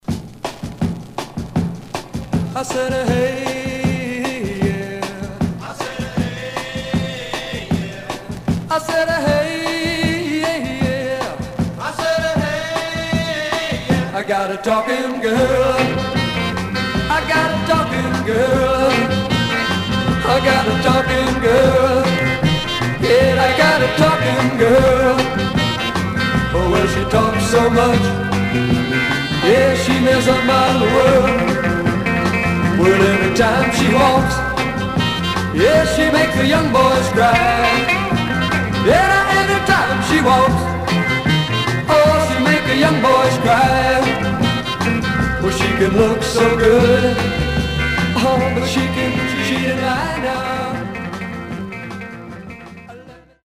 Surface noise/wear
Mono
Garage, 60's Punk Condition